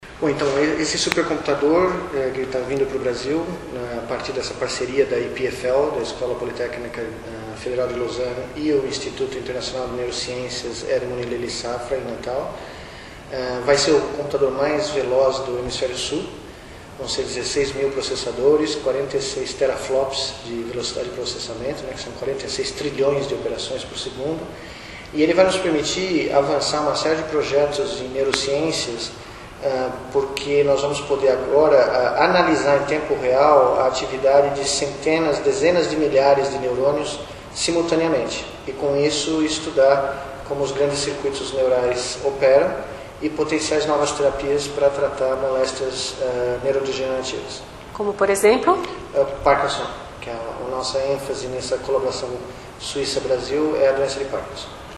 Miguel Nicolelis fala do supercomputador e que a ênfase será o estudo da doença de Parkinson.